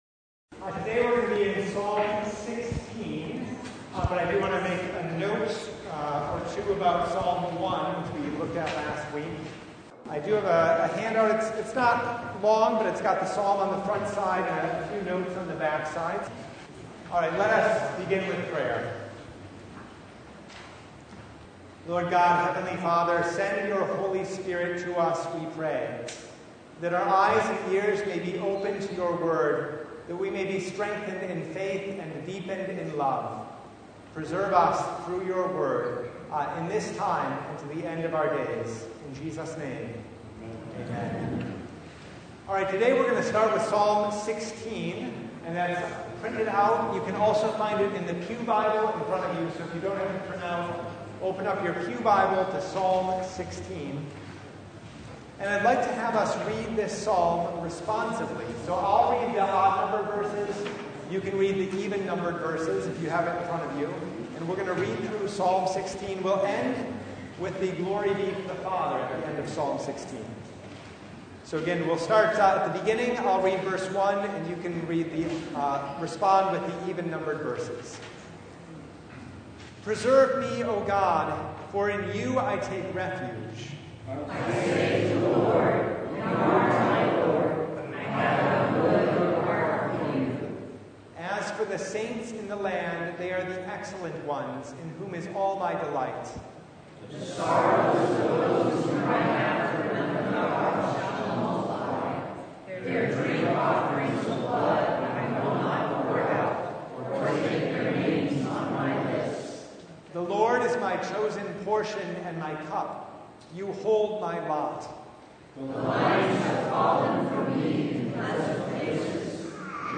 Psalm 16 Service Type: Bible Study Preserve me